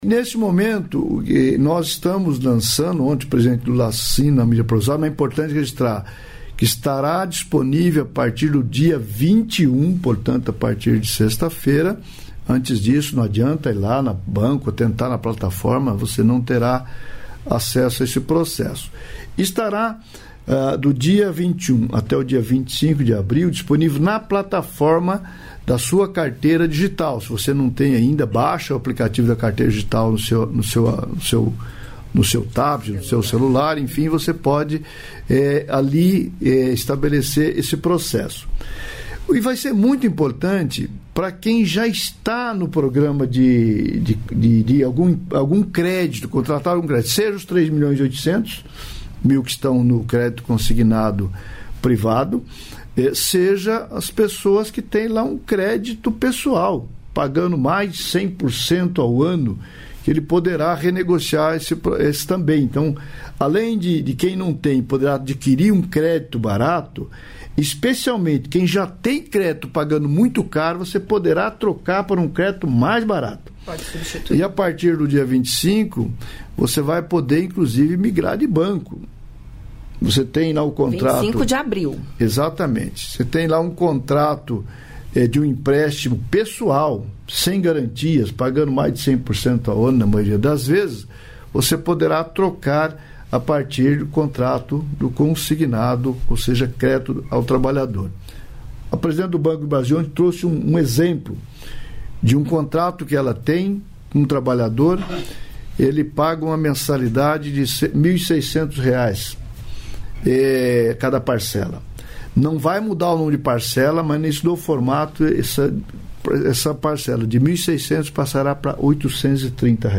Trecho da participação do ministro do Trabalho e Emprego, Luiz Marinho, no programa "Bom Dia, Ministro" desta quinta-feira (13), nos estúdios da EBC, em Brasília.